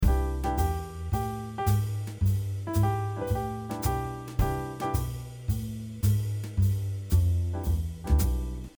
1. Playing with swing feel
As you can see, the eight notes in between strong beats are slightly delayed as opposed to being equally divided between strong beats.
playing-with-swing-feel.mp3